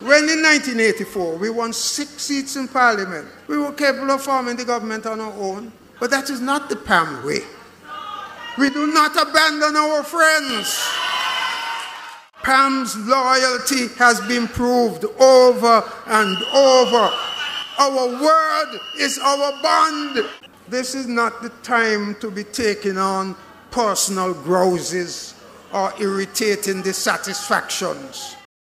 Dr. Sir Kennedy Simmonds, Former Leader of PAM and Former Prime Minister, said the party’s loyalty has been proven many times:
The Convention was held on Sunday, 13th March, 2022 in Cayon, St. Kitts, under the theme: “Keeping the Promise”.